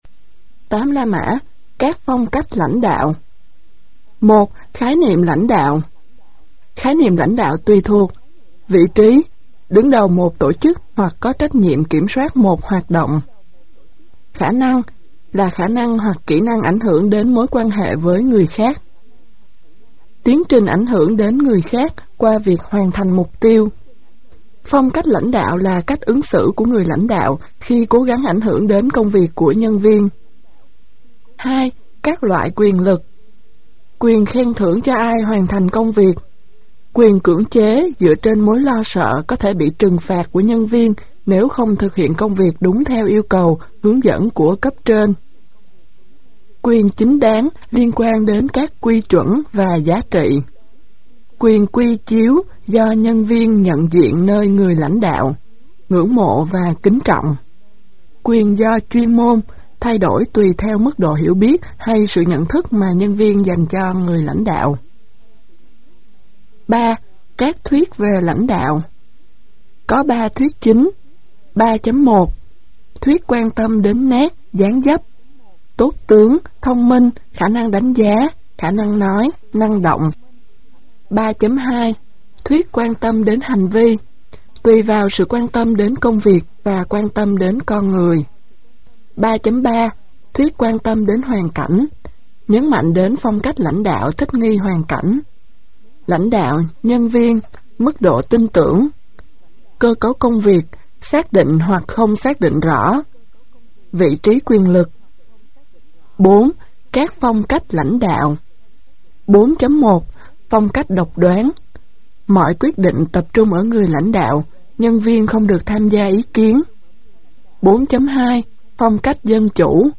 Sách nói Kỹ năng xây dựng và quản lí dự án - Sách Nói Online Hay